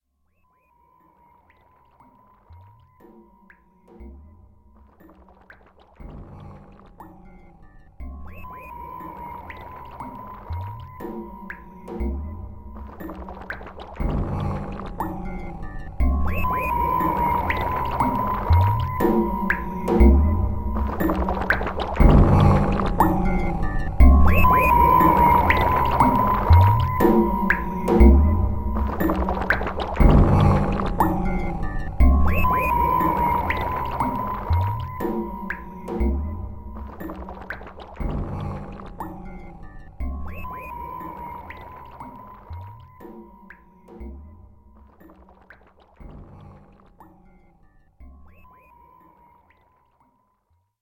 Songs in the key of weird.
the music is strange and adventurous!